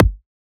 edm-kick-15.wav